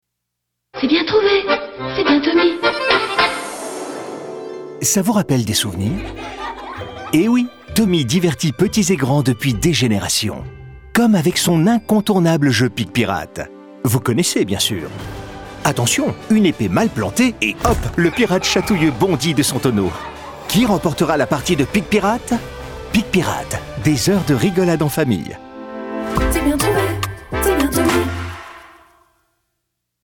Cette signature sonore sera modernisée et intégrée à la fin du spot audio pour renforcer l’identité de la marque et assurer la cohérence avec les autres supports de communication numérique et télévisuelle.
Spot Spotify